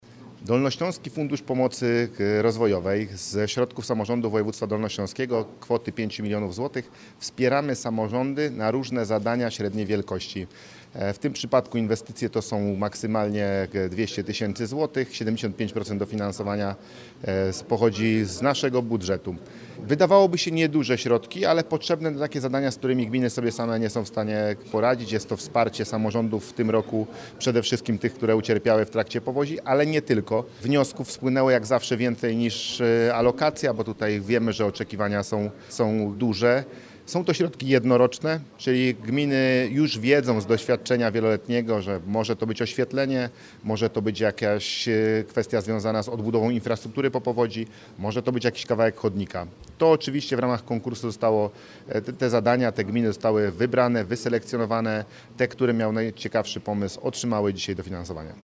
Mówi Paweł Gancarz, Marszałek Województwa Dolnośląskiego: